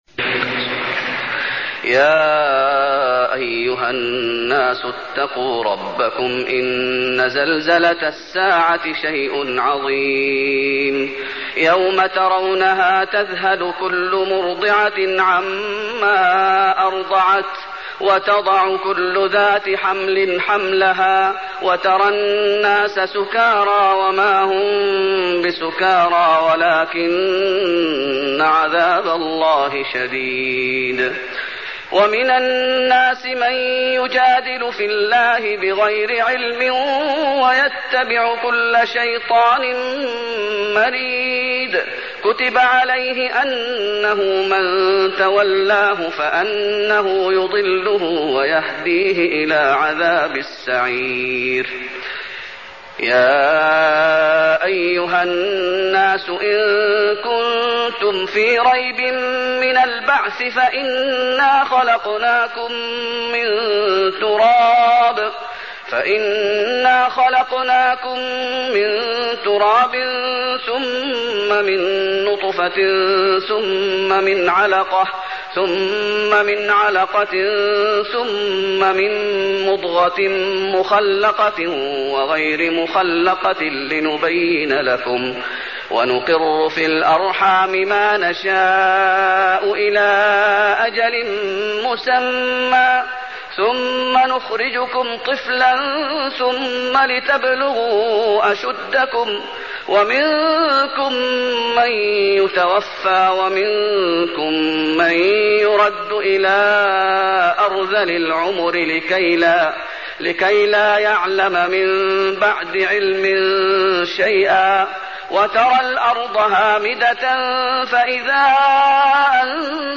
المكان: المسجد النبوي الحج